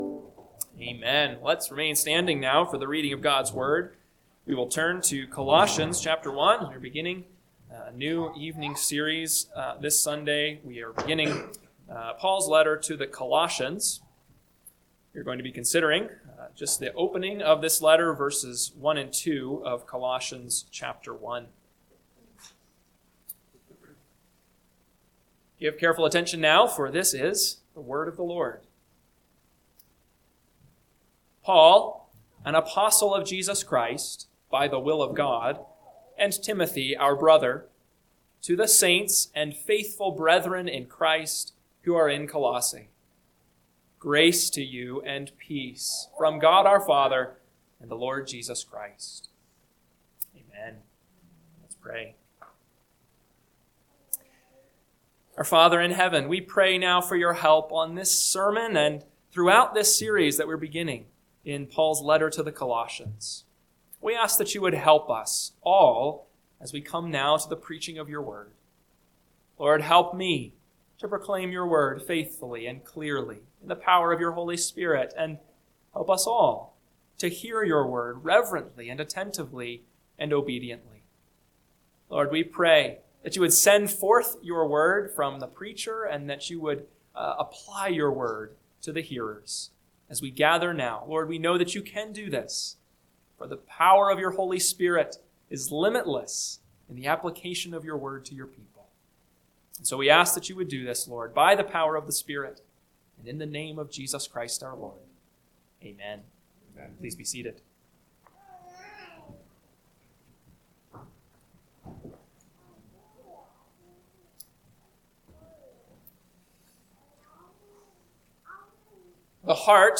PM Sermon – 12/14/2025 – Colossians 1:1-2 – Northwoods Sermons